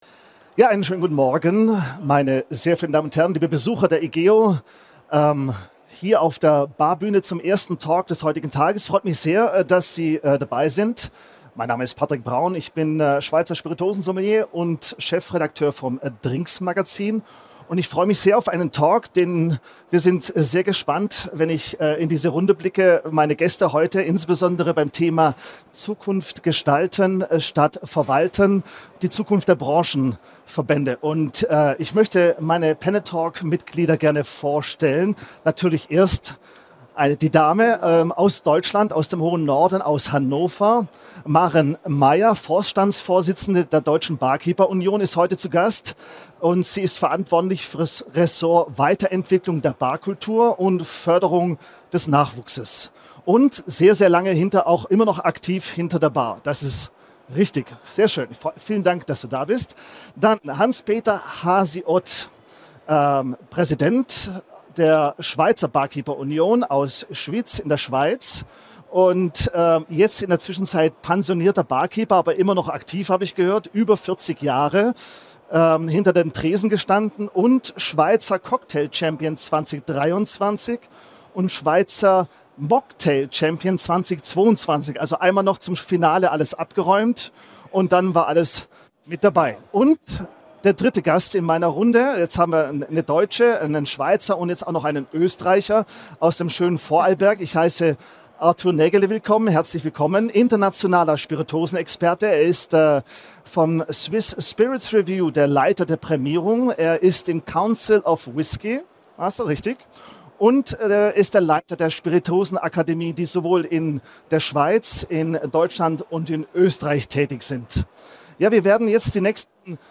In dieser Sonderfolge erfahren Sie mehr über die Bedeutung der politischen Einflussnahme der Branchenverbände, wie die Verbände die Ausbildung mitgestalten, welche Rolle Competitions spielen und wie die Attraktivität des Berufs hinter der Theke gesteigert werden kann. Das Podiumsgespräch fand am 15. November 2025 auf der Bühne der Sonderschau Barbesuch der Igeho 2025 statt.